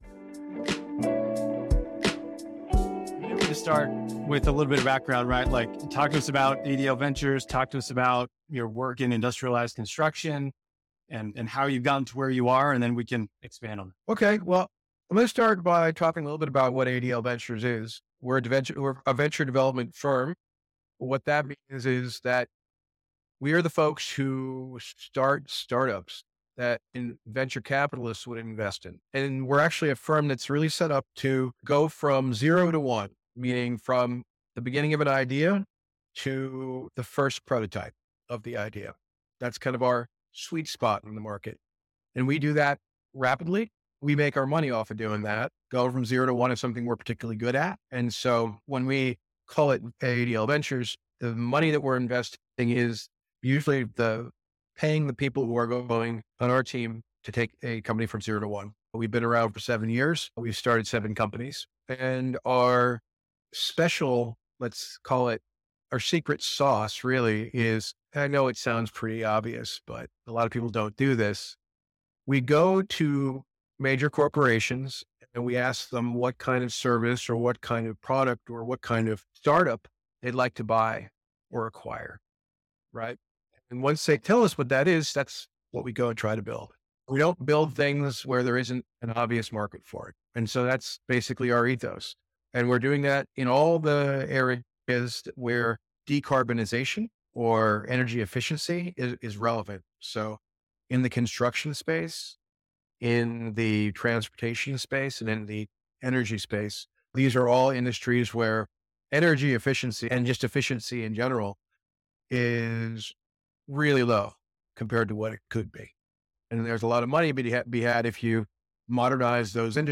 Explore critical insights for manufacturers and representatives in this in-depth interview focused on the evolving landscape of industrialized construction.